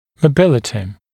[məu’bɪlətɪ][моу’билэти]подвижность, мобильность